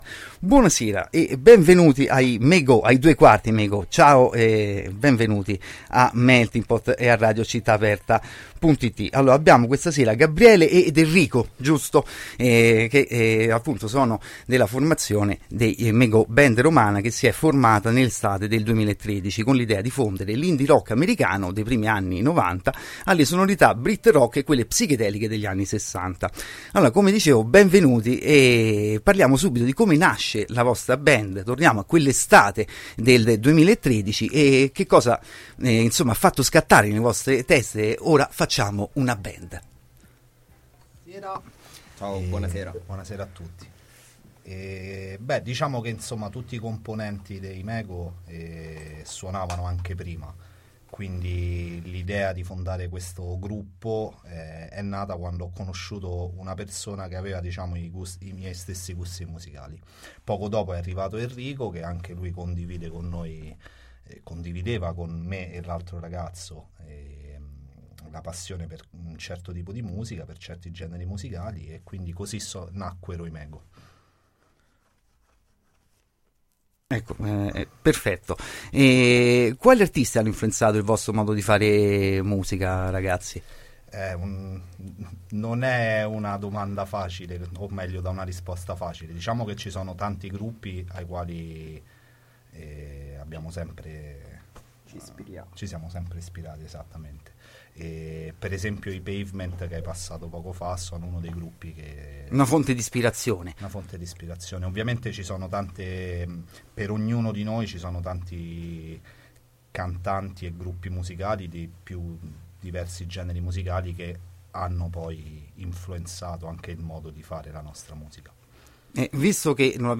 INTERVISTA AI MEGO A MELTINGPOT | Radio Città Aperta